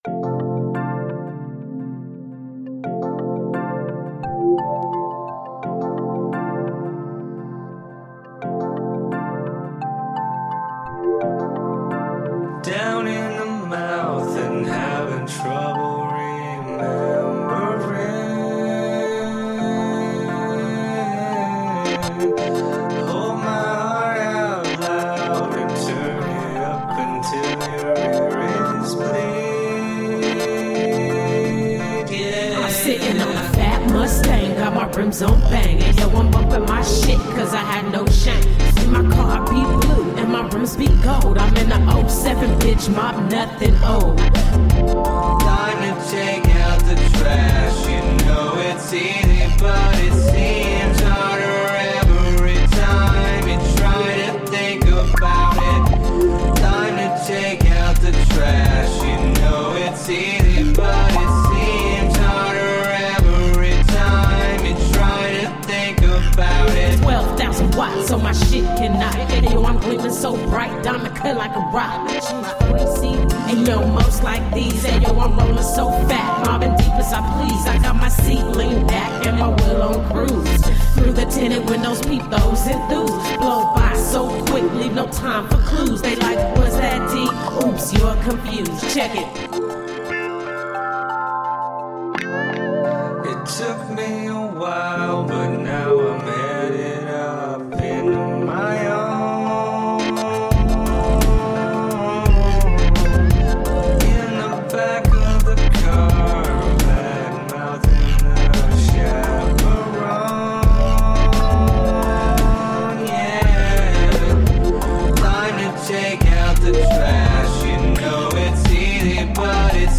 Groove soaked ambient chill.
Alt Rock, Rock, Remix